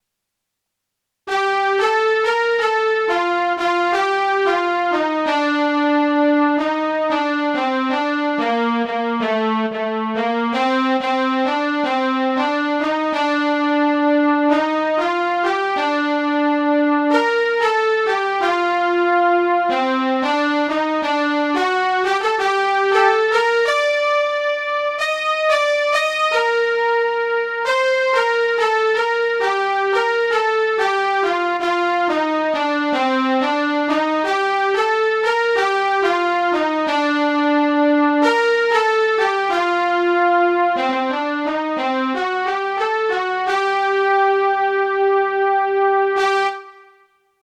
Kann mir jemand helfen?4/4 Takt 91 Bpm.
Ich glaube es ist 6/8tel und auch 91 Bpm?